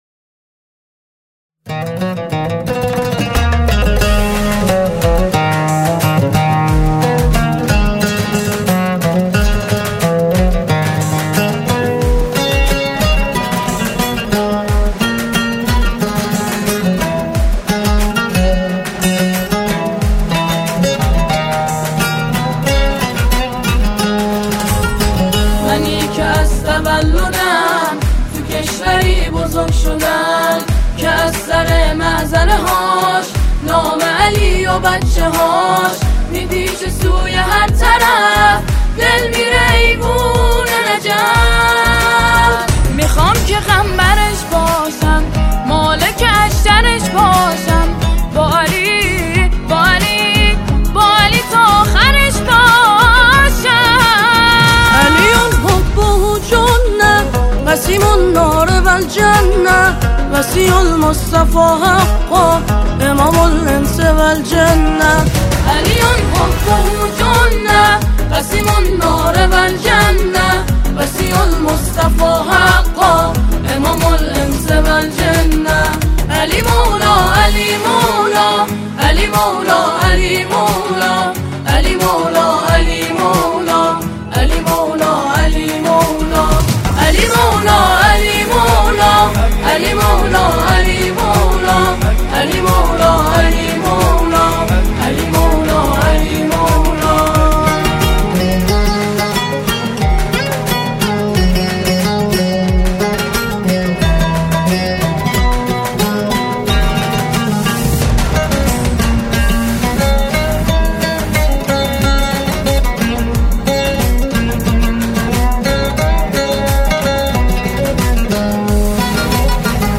سرودهای اعیاد اسلامی